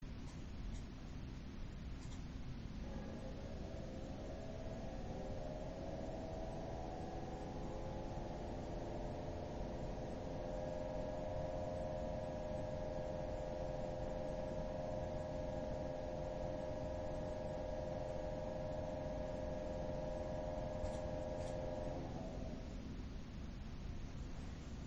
Zwei der drei Lüfter haben aus meiner Sicht zu viel Lagergeräusche.
Ich habe anbei ein paar Aufnahmen gemacht (ca. 20cm Abstand):
• 3x jeden Fan einzeln mit ~1300rpm / 39%.
Lüfter 1 und Lüfter 3 sind problematisch.
fan1 1300rpm.mp3